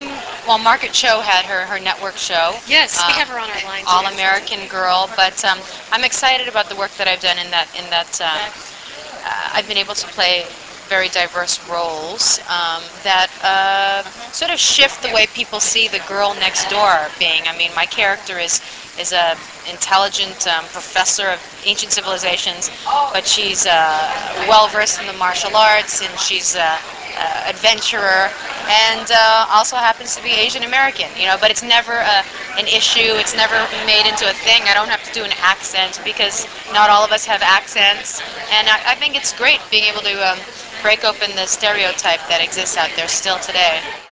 Tia was in Los Angeles as Mistress of Ceremonies of A Magazine's bi-coastal 10th anniversary gala.
tia_interview.rm